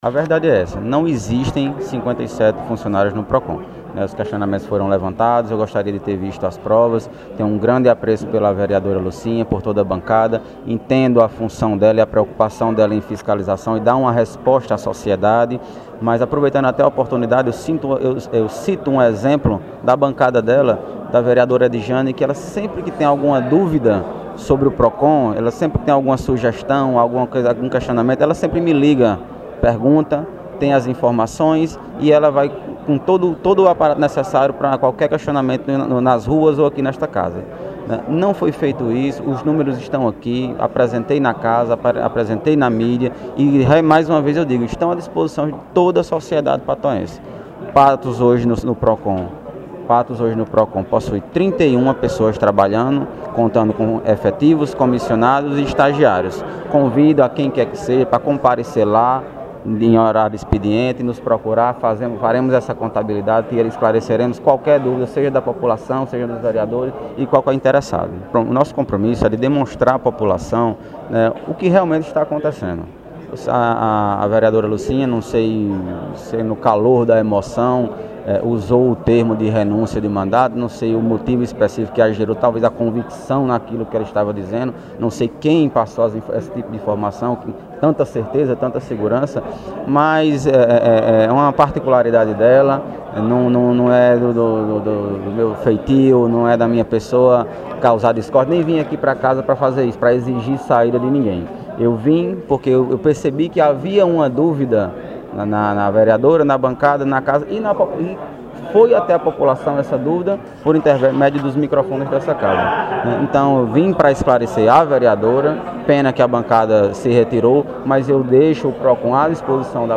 Aberta, alguns minutos depois, a sessão extraordinária, que foi realizada com a presença de toda a bancada situacionista, com exceção do presidente Sales Júnior, que estava ausente, foi concedida a palavra ao secretário do PROCON, Bruno Maia, para que desse os esclarecimentos necessários.
Secretário do PROCON/Patos, Bruno Maia –